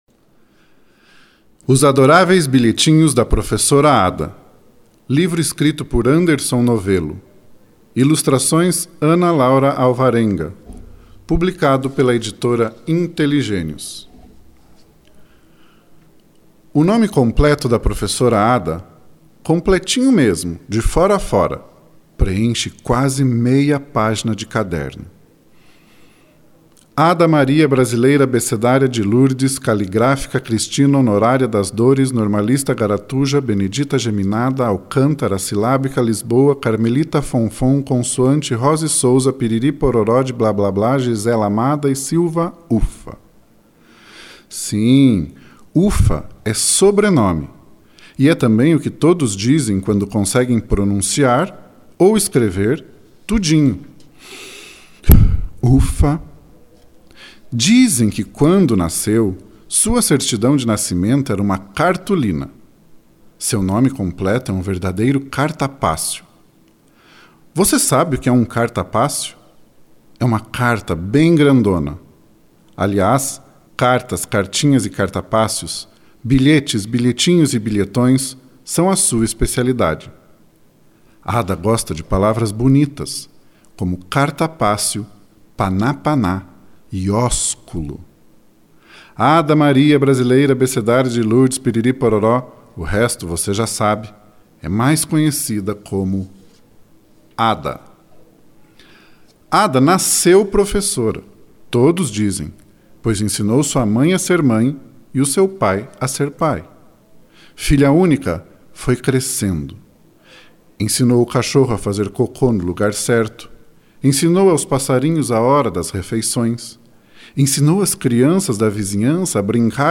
Leitura Guiada
LEITURA-OS-ADORAVEIS-BILHETINHOS-DA-PROFESSORA-ADA-NARRACAO.mp3